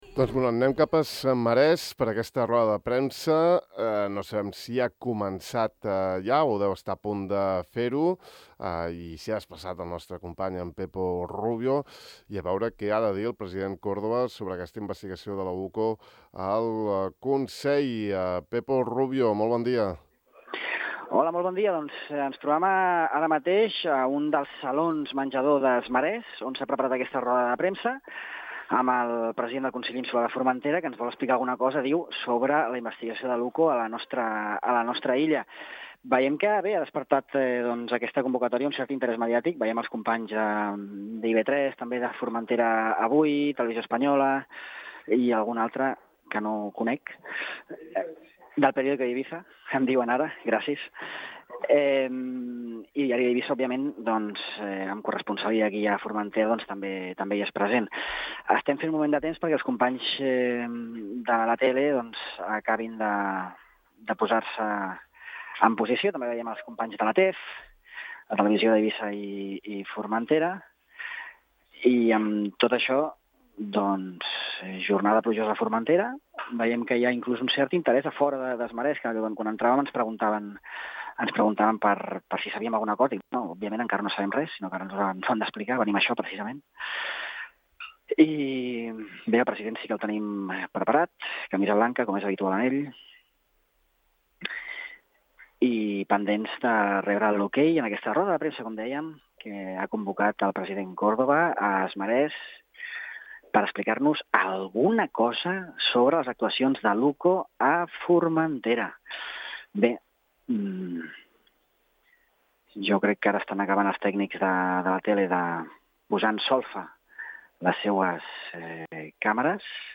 Funcionaris insulars, polítics i algun empresari, "implicats" en els "possibles delictes" que el president ha traslladat a Anticorrupció (inclou roda de premsa completa)